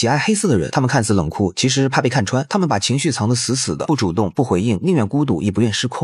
Empatisk psykologi berättarröst
Leverera djupgående psykologiska insikter med en lugn, auktoritativ och empatisk AI-röst designad för mentalhälsoutbildning och terapeutiskt berättande.
Psykologi berättande
Empatisk ton